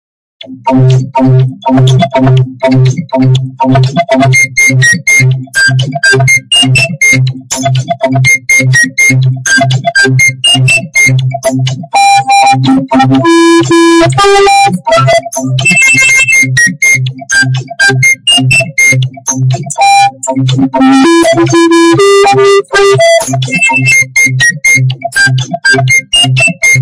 Meme Sound Effect